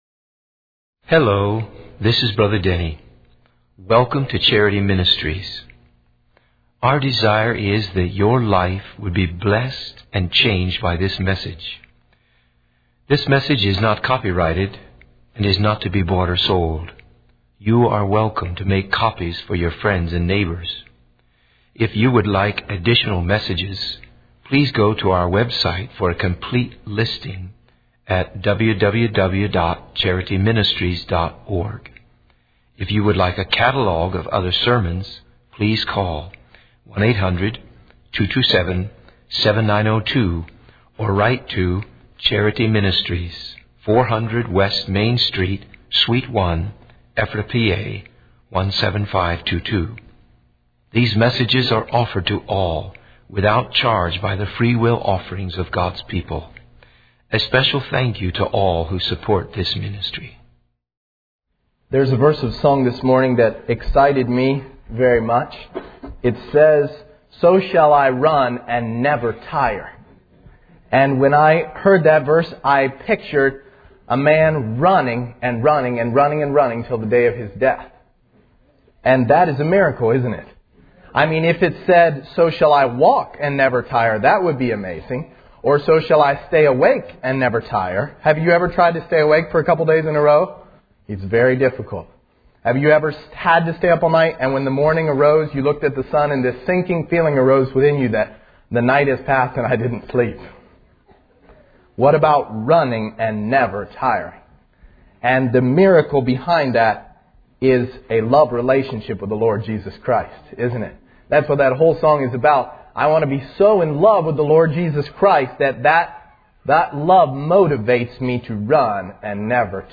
In this sermon, the speaker shares a personal story about working with his father and the importance of making the most of free time in ministry. He emphasizes the need for open and honest communication with one's spouse, especially in the context of ministry.